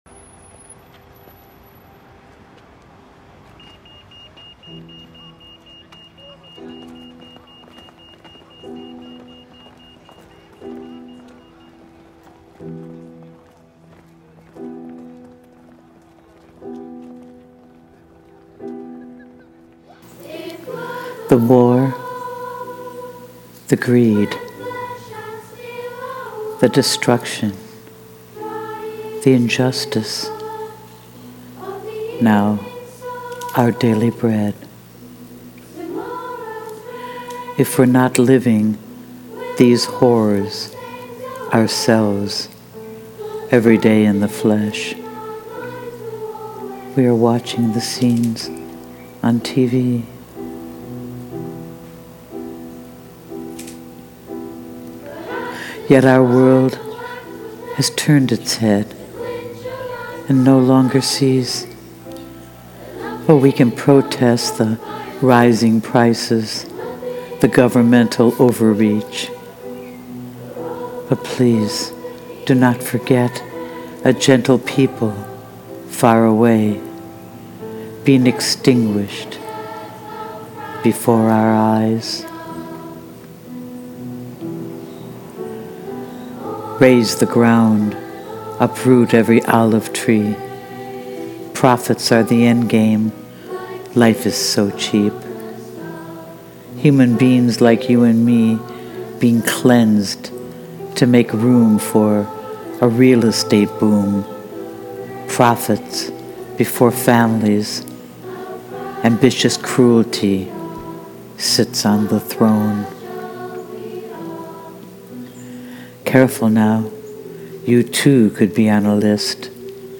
Sting’s song really fitted as the aptly chosen background to your haunting voice.